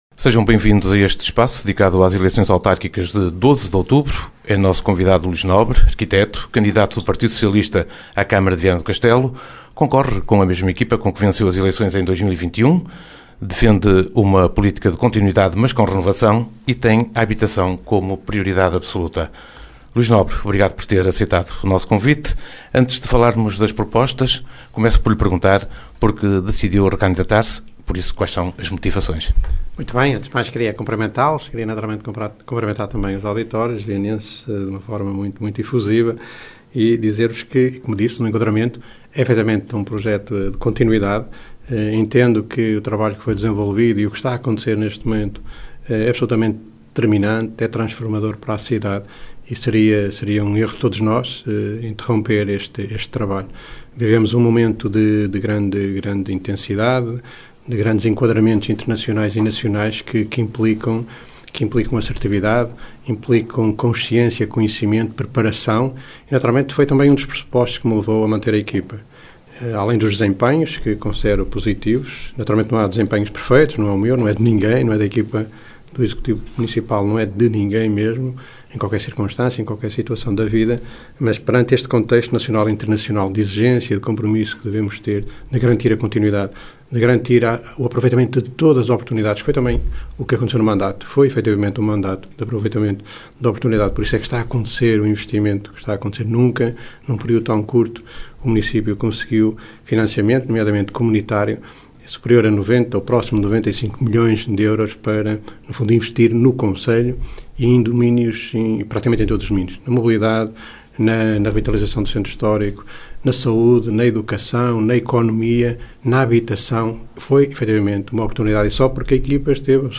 Ação conjunta da Rádio Geice FM e da Rádio Alto Minho, que visa promover um ciclo de entrevistas aos candidatos à presidência da Câmara Municipal de Viana do Castelo.